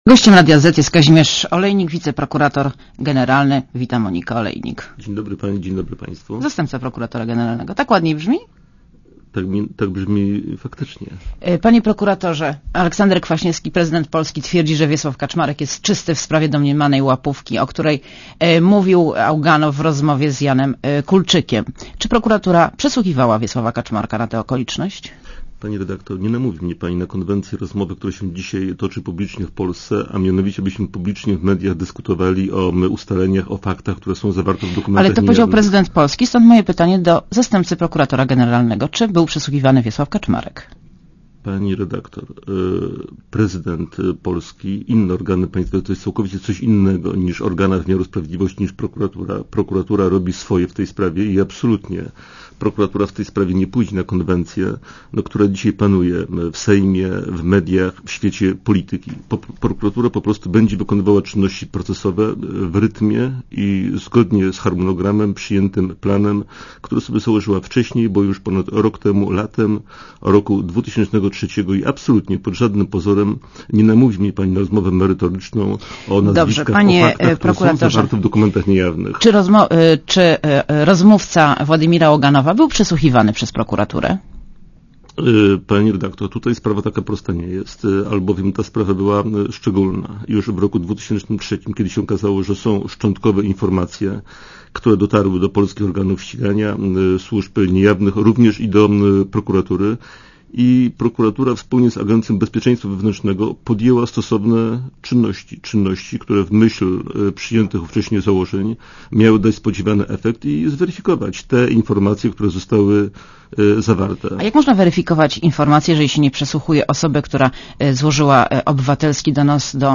Posłuchaj wywiadu Gościem Radia Zet jest zastępca prokuratora generalnego, Kazimierz Olejnik.